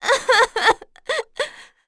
Juno-Vox_Sad.wav